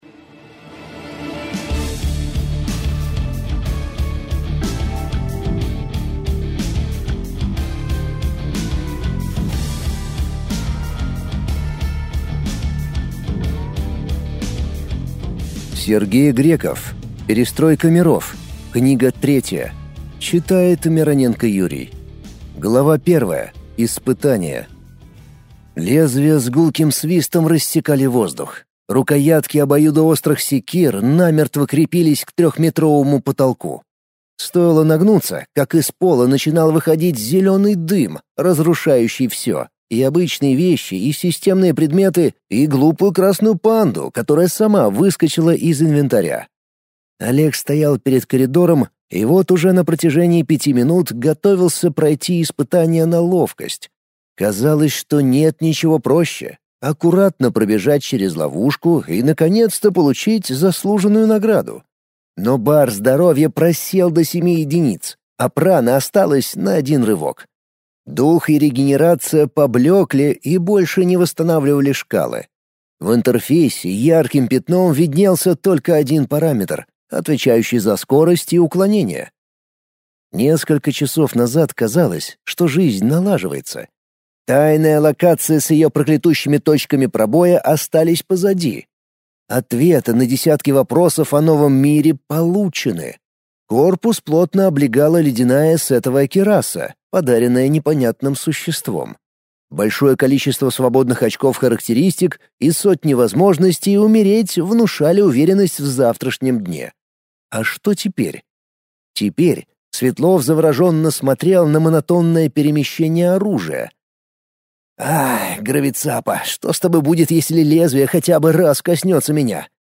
Аудиокнига Перестройка миров. Книга 3 | Библиотека аудиокниг